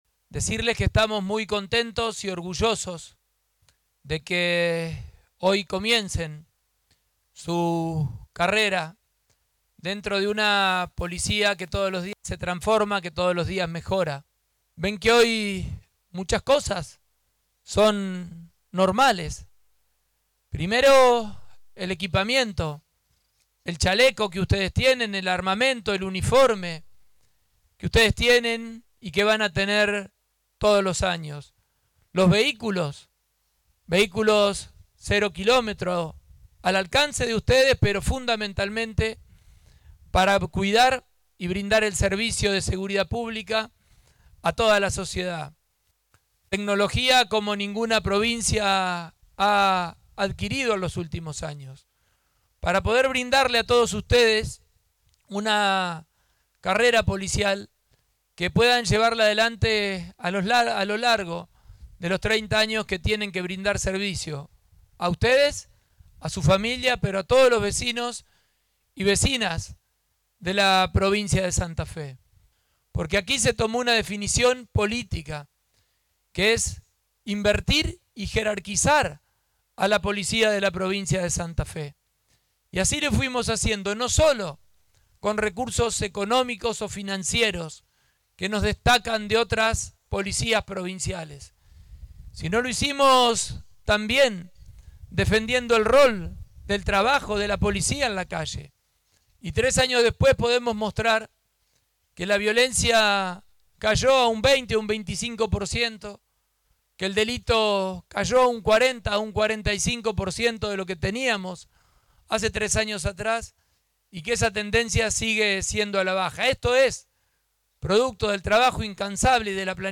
El gobernador de Santa Fe, Maximiliano Pullaro, encabezó este lunes en la Unidad Regional II de Rosario el acto de puesta en funciones de 500 nuevos suboficiales de la Policía de Santa Fe, en el marco del fortalecimiento del plan integral de seguridad.
Durante la ceremonia, el mandatario dio la bienvenida a los agentes y destacó el proceso de transformación de la fuerza.